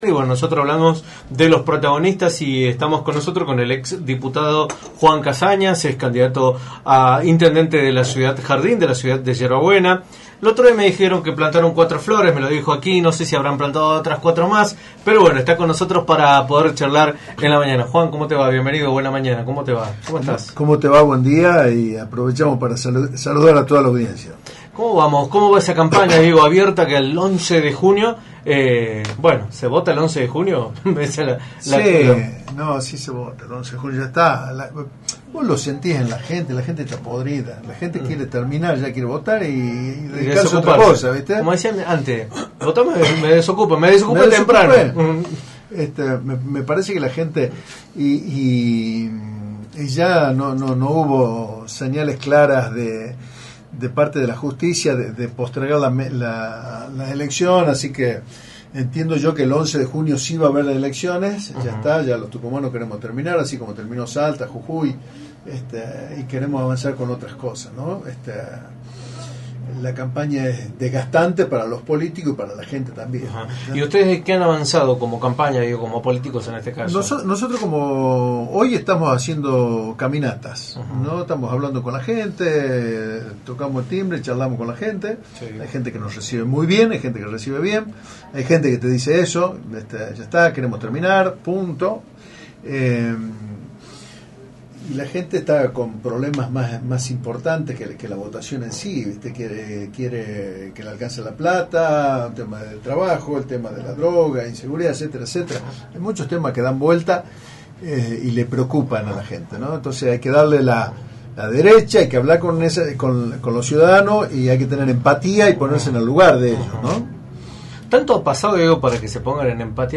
Juan Casañas, ex Diputado y candidato a Intendente de la Municipalidad de Yerba Buena, visitó los estudios de Radio del Plata Tucumán, por la 93.9, y abordó el escenario electoral de la provincia de cara a las elecciones del próximo 11 de junio.